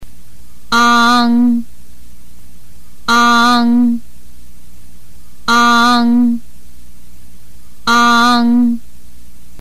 発音は舌先をどこにもつけずに口を開けたまま「ン」 と発音します。
国語の母音　鼻母音をクリックすると各鼻母音４回を繰り返して聞くことができます。
中国語の母音　鼻母音 日本語の「案外」の「アン」。